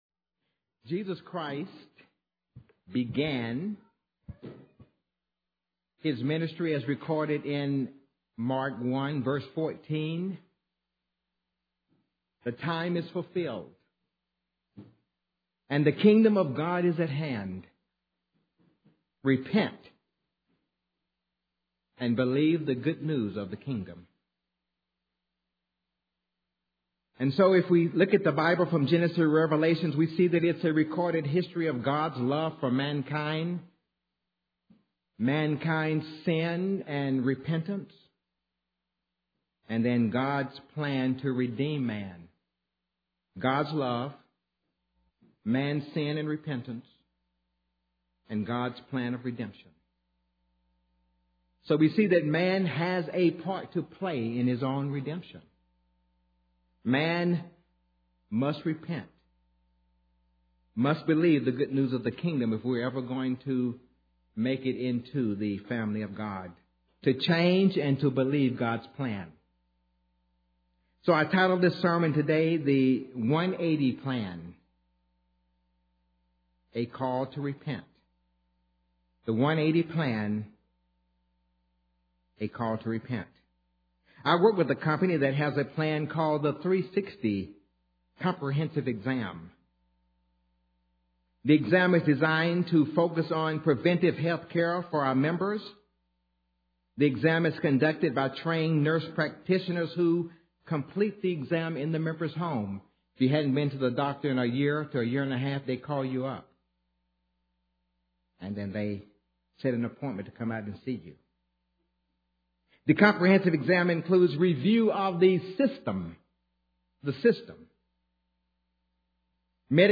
Given in Nashville, TN
UCG Sermon Studying the bible?